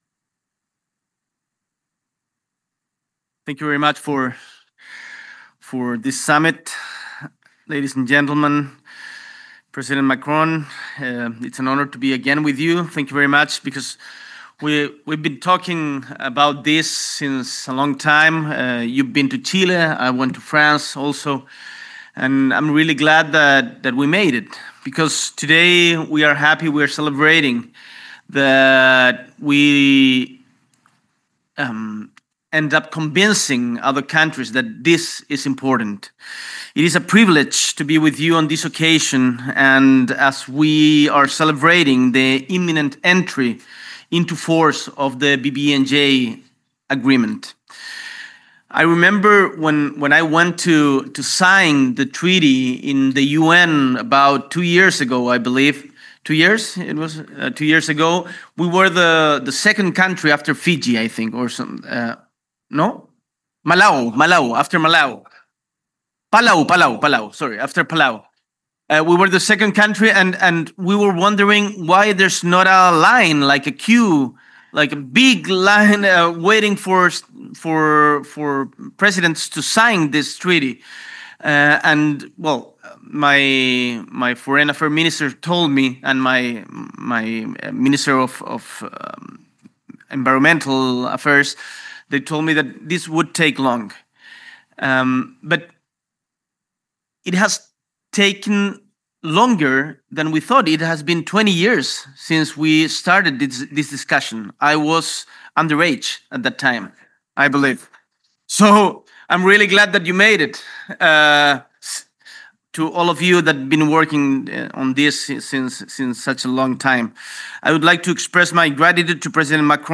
S.E. el Presidente de la República, Gabriel Boric Font, participa del encuentro From Nice to New York: Activating the High Seas Treaty, para conmemorar la entrada en vigor del Acuerdo sobre la Conservación y Uso Sostenible de la Biodiversidad Marina